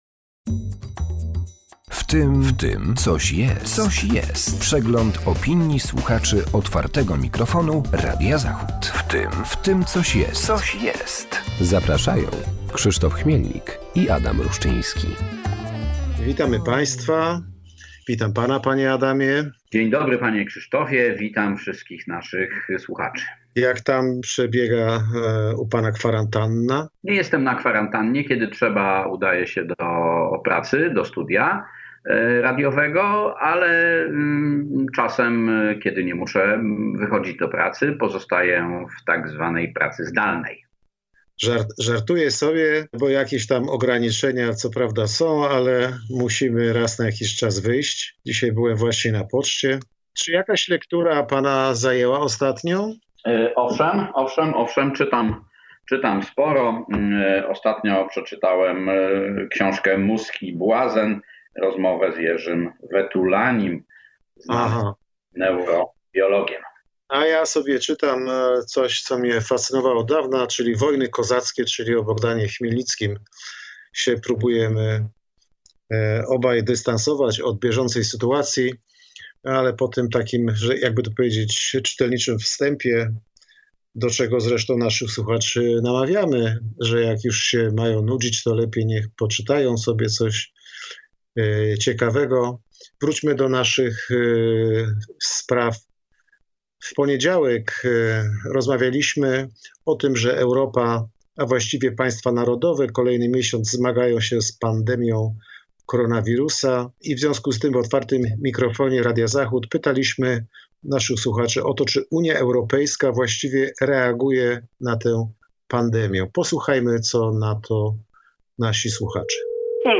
W cotygodniowej audycji przypominamy głosy słuchaczy Otwartego Mikrofonu oraz komentujemy tematy z mijającego tygodnia.